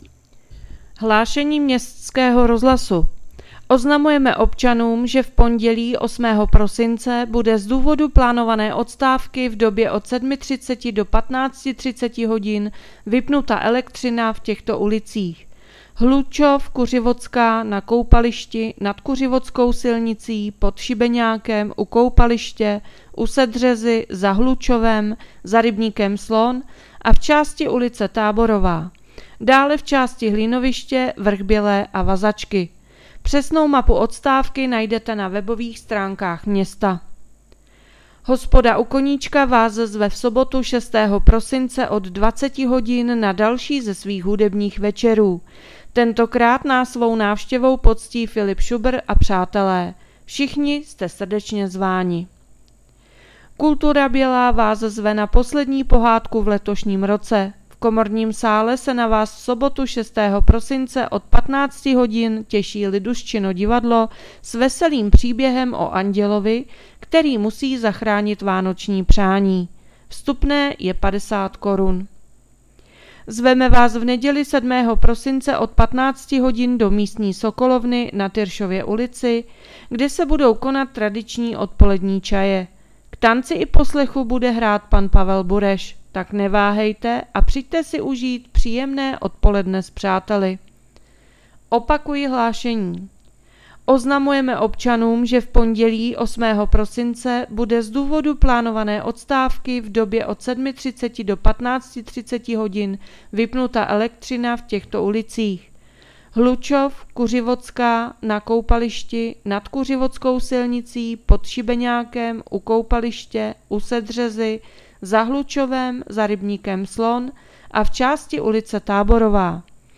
Hlášení městského rozhlasu 5.12.2025